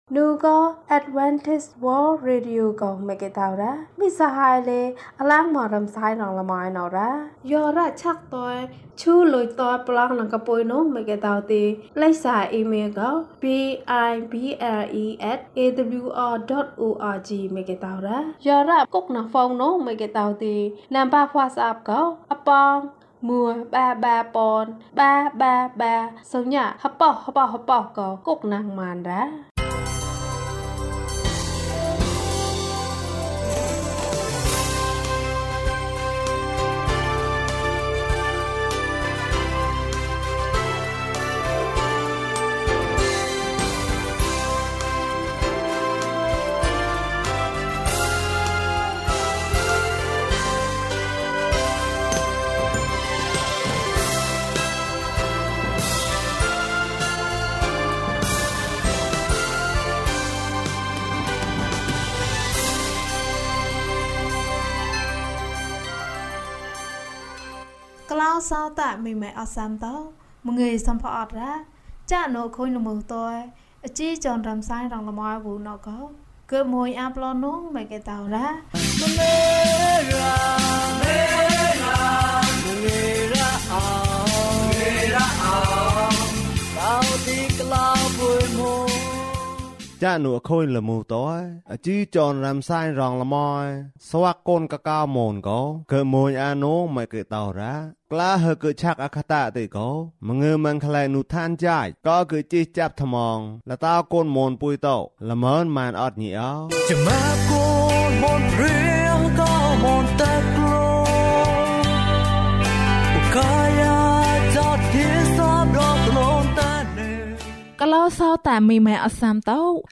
ငါ့ဆုတောင်းသံကို ယေရှုကြားတယ်။ ကျန်းမာခြင်းအကြောင်းအရာ။ ဓမ္မသီချင်း။ တရားဒေသနာ။